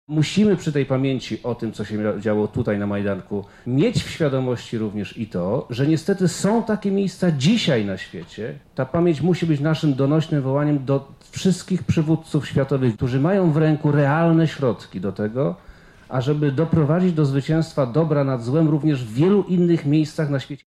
„To, że żyjemy w kraju, który od kilkudziesięciu lat nie zaznał wojny to zasługa pamięci o tych wydarzeniach” mówi Przemysław Czarnek – wojewoda lubelski: